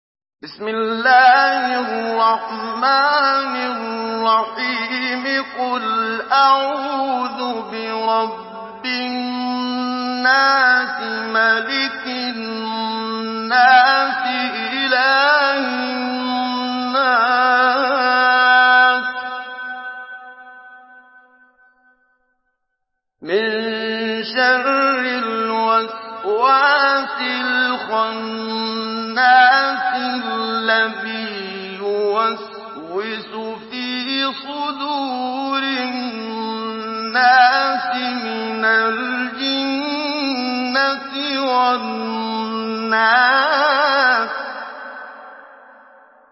Surah الناس MP3 by محمد صديق المنشاوي مجود in حفص عن عاصم narration.